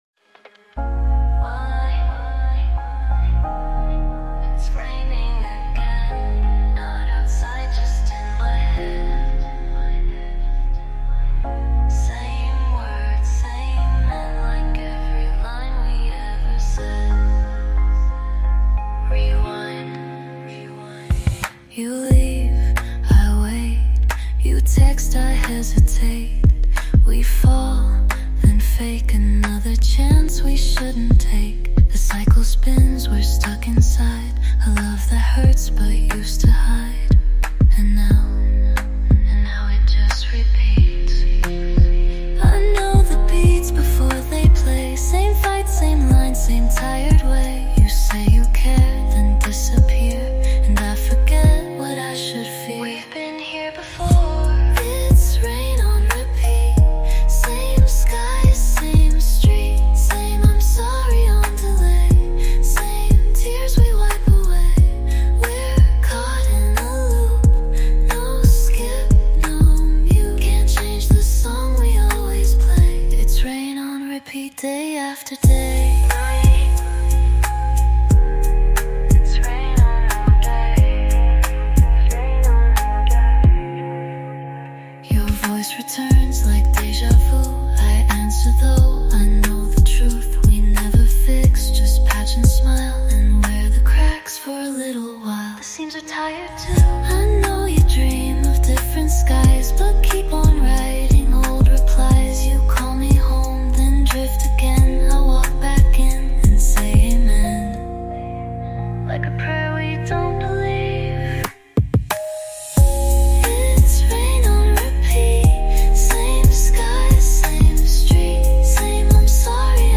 Chill Lo-fi Beats for Focus and Late Night Work